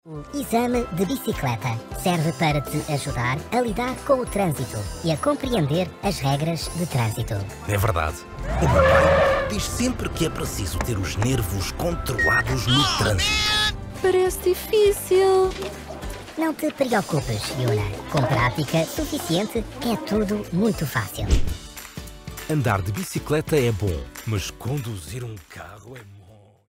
Animation
Vicoustic-Audiokabine, Neumann TLM103-Mikrofon, Neve 1073SPX-Vorverstärker, Apogee Duet 2-Schnittstelle, Highspeed-Kabelinternet.
BaritonBassTiefNiedrigSehr niedrig
FreundlichWarmRuhigFesselndVertrauenswürdig